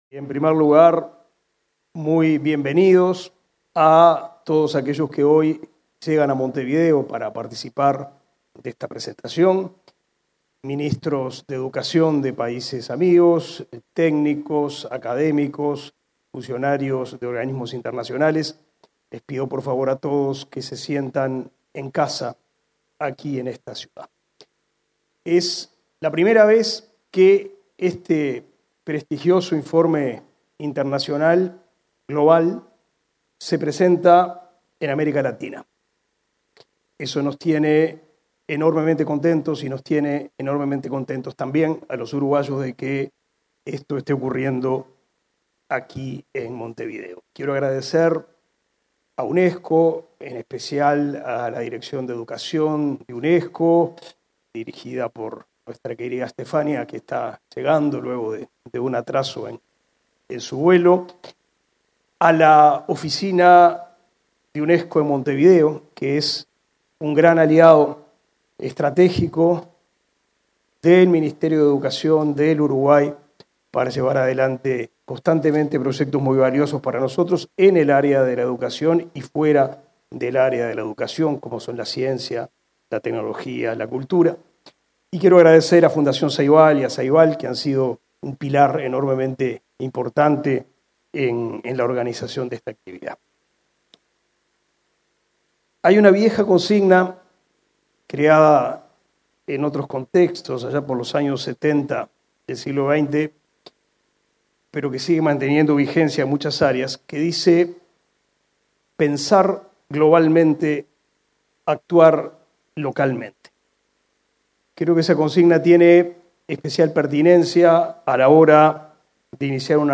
Palabras del ministro de Educación y Cultura, Pablo da Silveira
En el marco de la presentación del informe de 2023 sobre tecnología en la educación en el mundo, este 26 de julio, se expresó el ministro de Educación